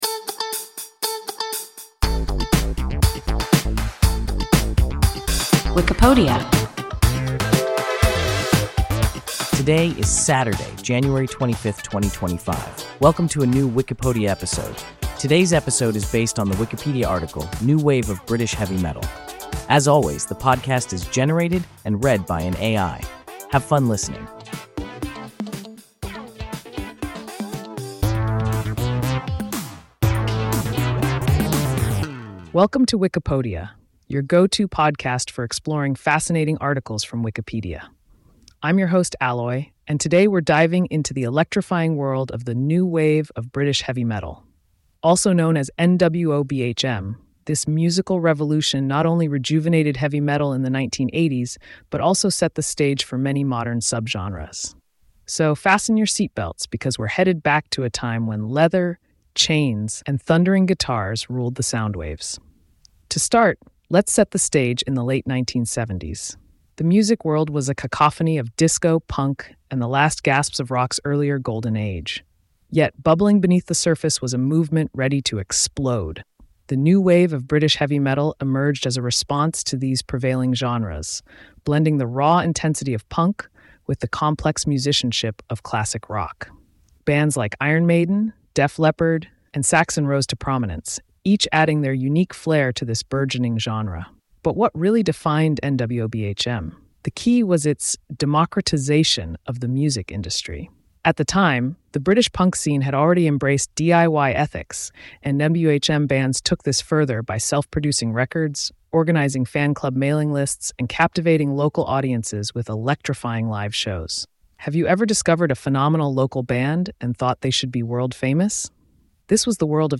New wave of British heavy metal – WIKIPODIA – ein KI Podcast